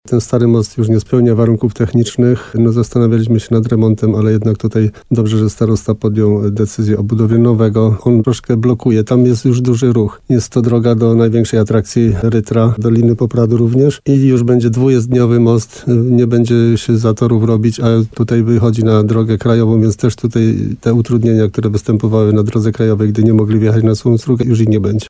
Nie będzie też utrudnień na drodze krajowej, które tworzyły się, gdy kierowcy chcieli zjechać z niej na Suchą Strugę – mówił wójt Jan Kotarba w programie Słowo za Słowo na antenie RDN Nowy Sącz.